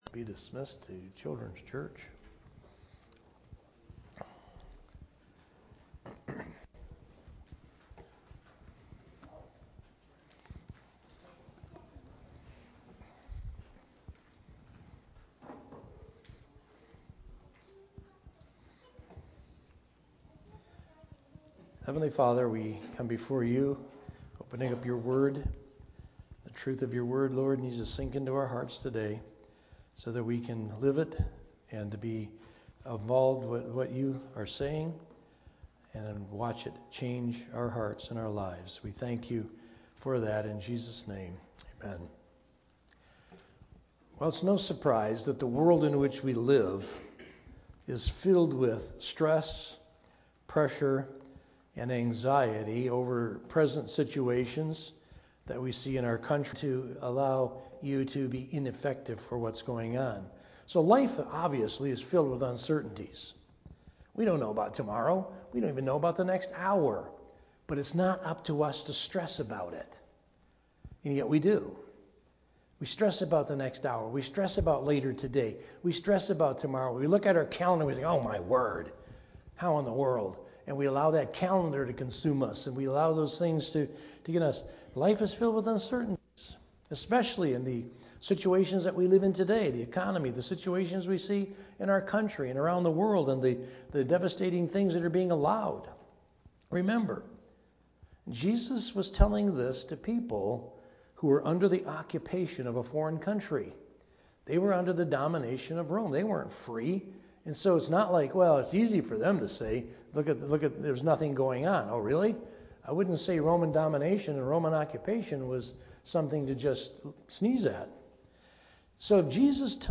A message from the series "Sunday Morning - 11:00."